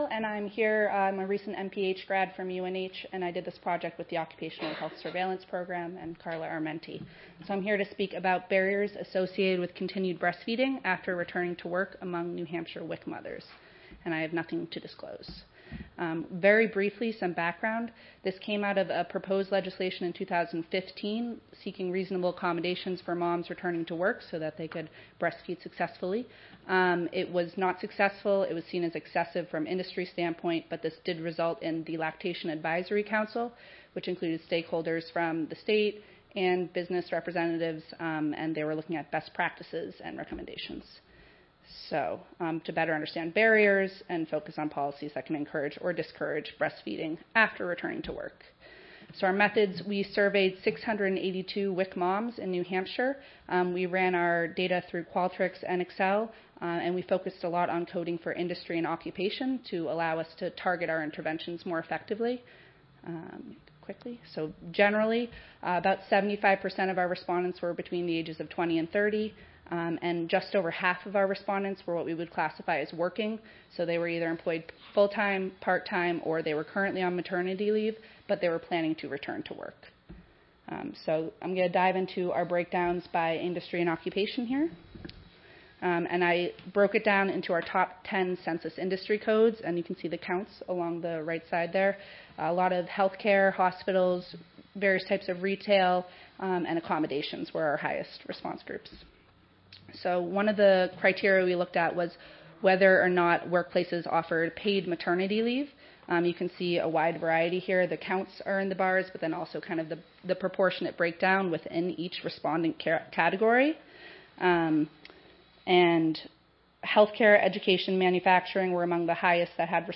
Audio File Recorded Presentation